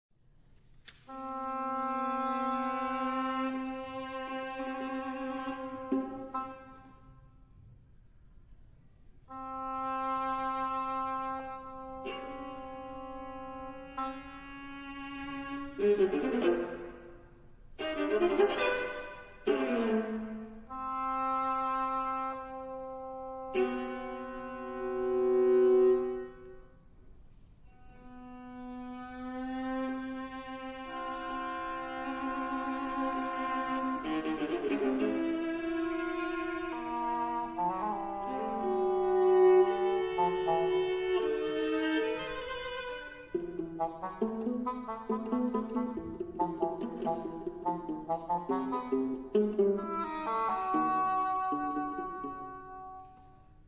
For English horn and viola.